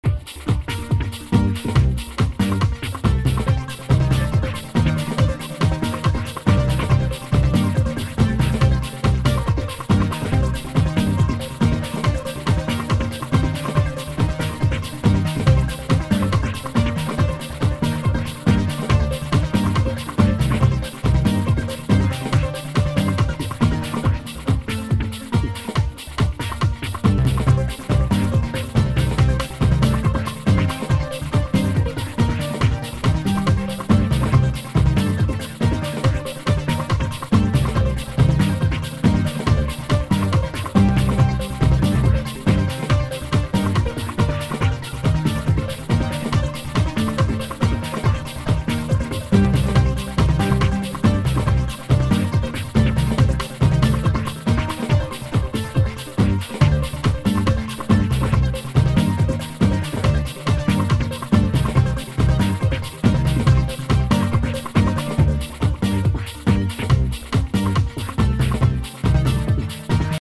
On obtient comme résultat une musique assez inhabituelle, structurée mais sans thème répétitif.
Voici 3 ambiances musicales pour illustrer les capacités de la Société Henon.